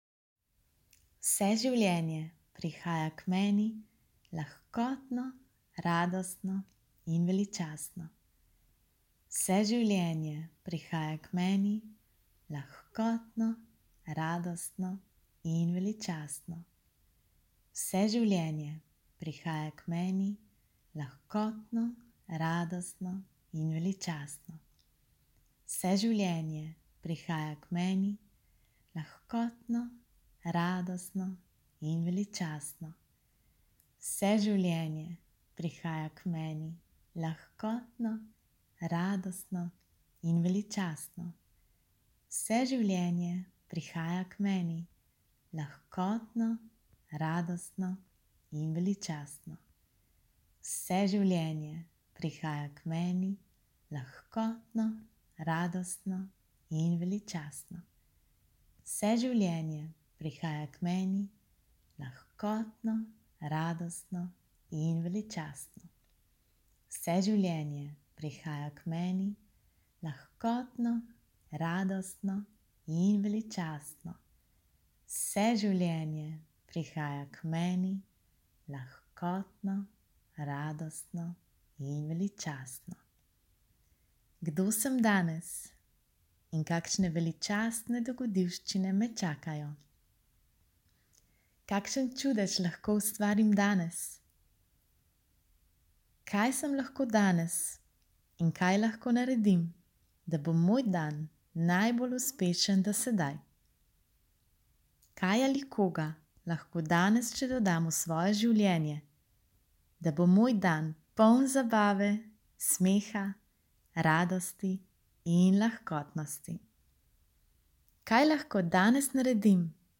Na njem 10x ponovim mantro Access Consciousnessa in postavim nekaj vprašanj, s katerimi se odpremo za lepši dan.
mantra-za-dobro-jutro